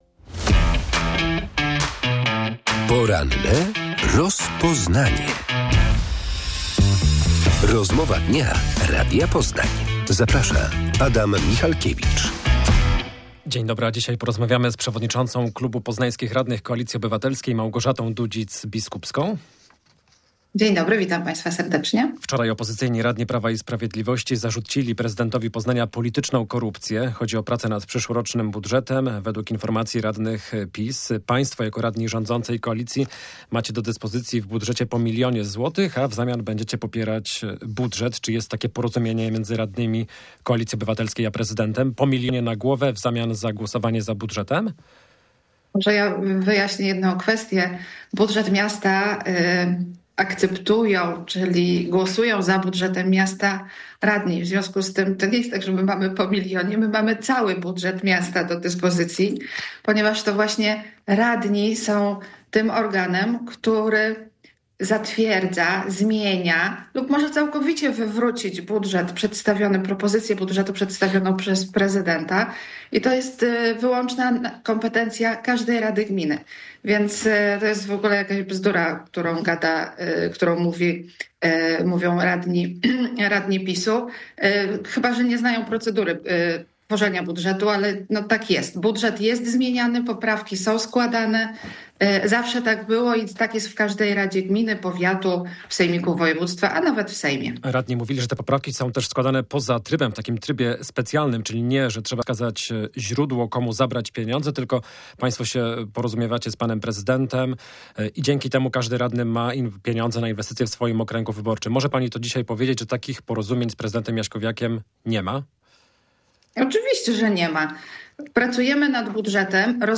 Czy poznańscy radni KO poprą budżet na przyszły rok, podwyżki opłat w strefie i komunikacji miejskiej? Gościem Radia Poznań była przewodnicząca klubu radnych Małgorzata Dudzic-Biskupska.